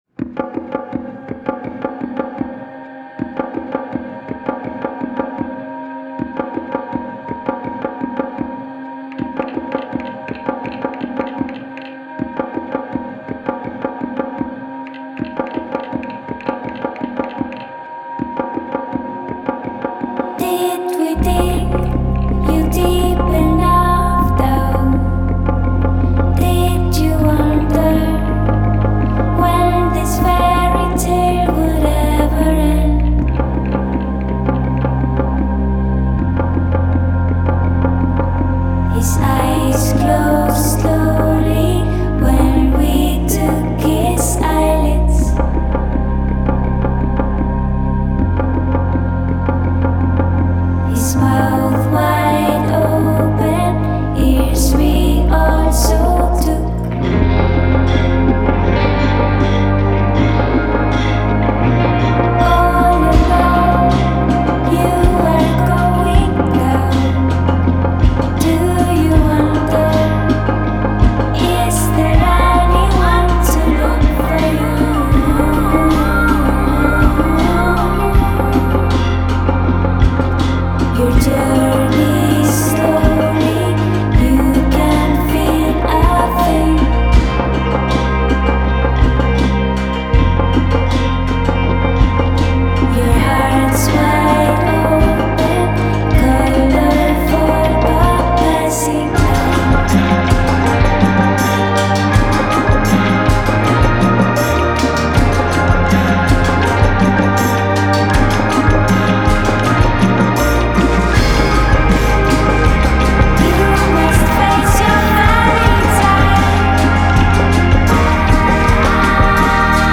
Icelandic singer-songwriter